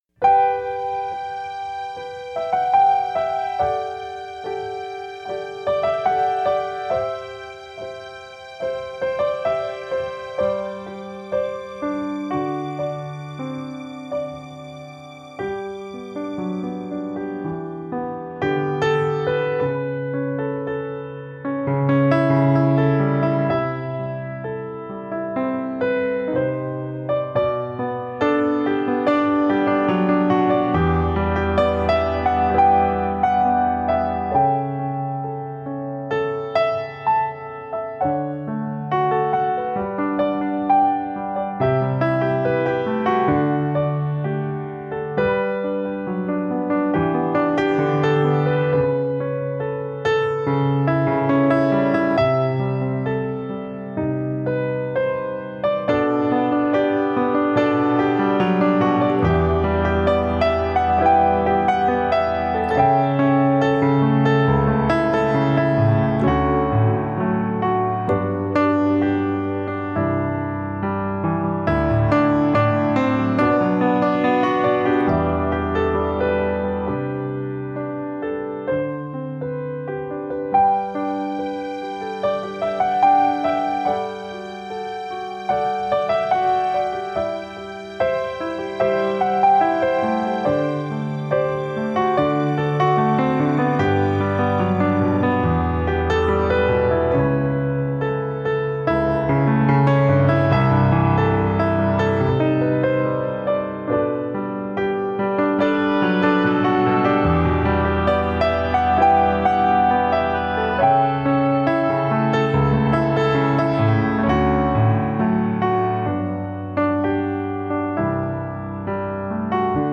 Genre: New Age, Instrumental, Piano.